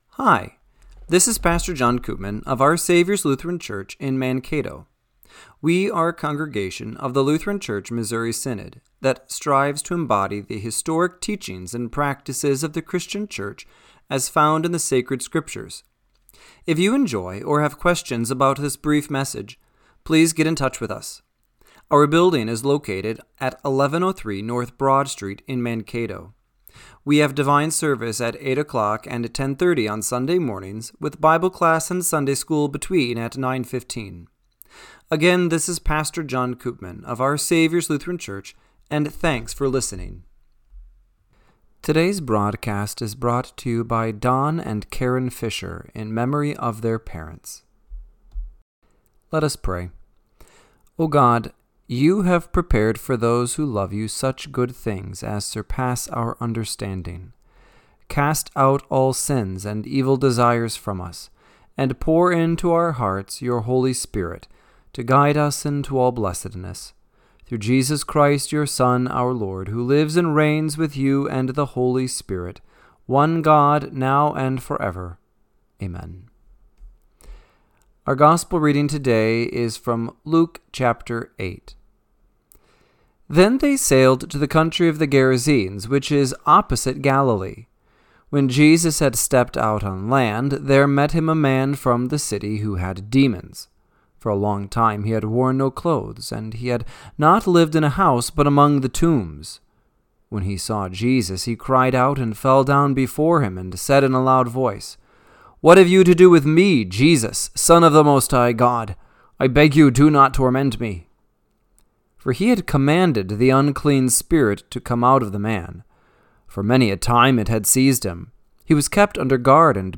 Radio-Matins-6-22-25.mp3